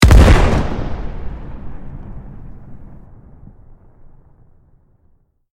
medium-explosion-4.ogg